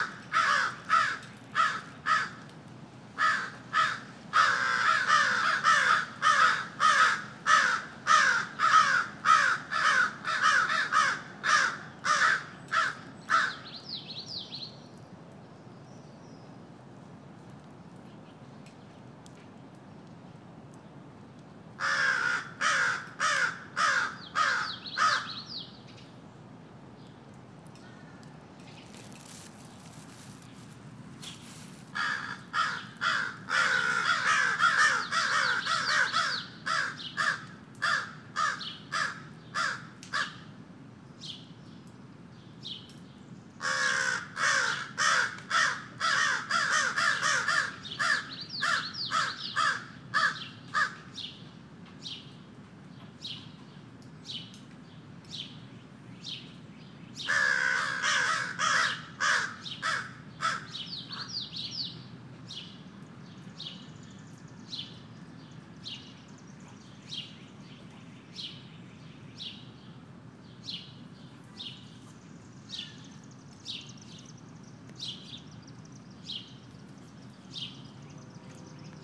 Crows protecting their nest